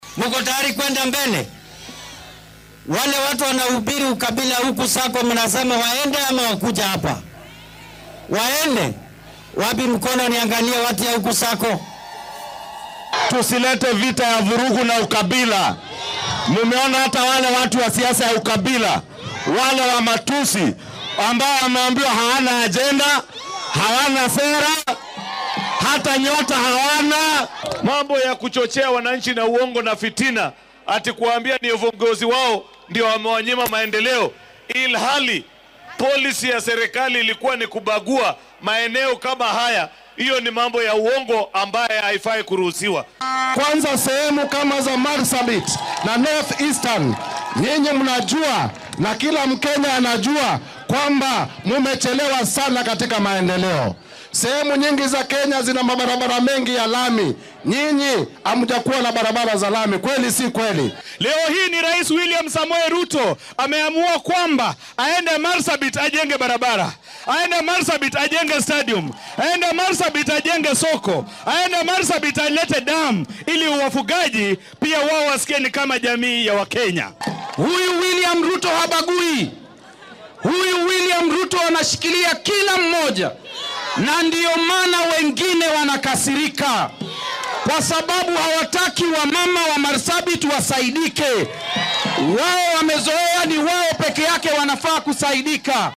Hoggaamiyeyaashan ayaa arrintan ka sheegay intii ay ka qaybgalayeen barnaamij kor loogu qaadayo awood siinta haweenka oo ka dhacay ismaamulka Marsabit.